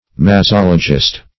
Mazologist \Ma*zol"o*gist\, n. One versed in mazology or mastology.